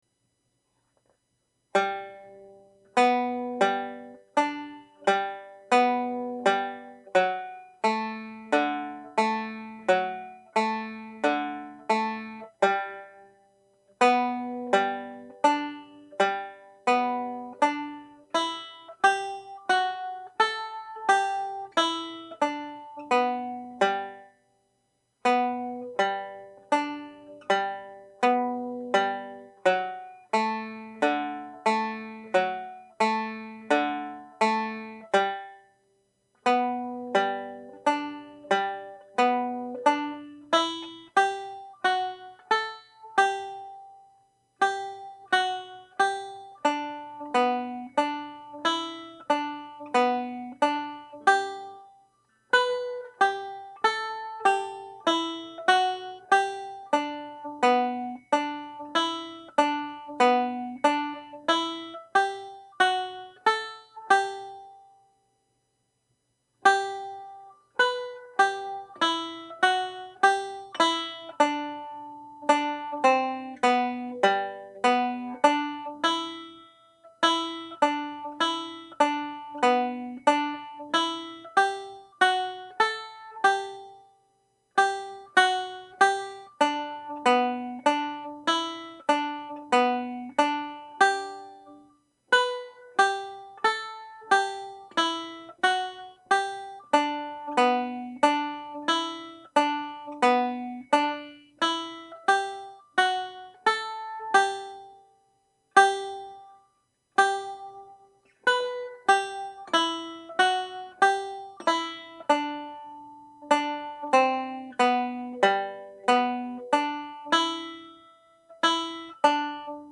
Single Reel (G Major)